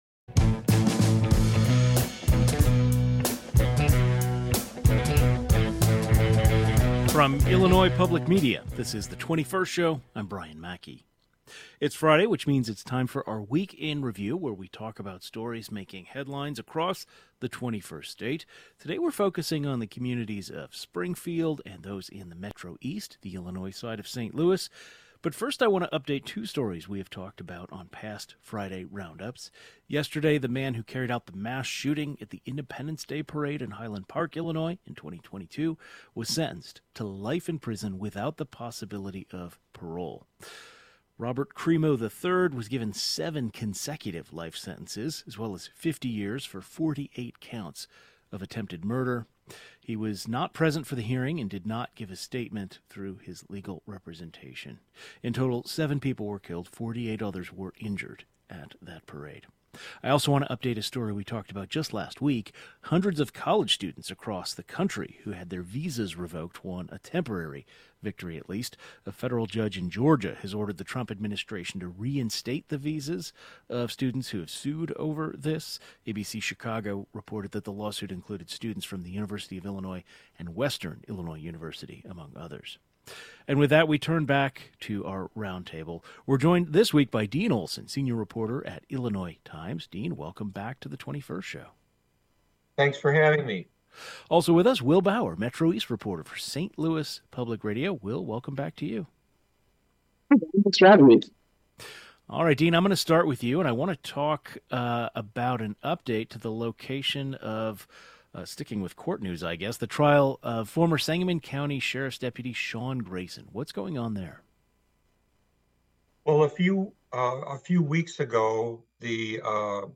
It's Friday, which means it's time for the Reporter Roundtable, where we talk about stories making headlines across the state. In today's roundtable: Springfield and the Metro East as well as an update in the Highland Park Fourth of July mass shooting case.